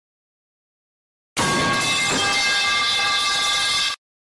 Metal_pipe_falling_sound_effect.mp3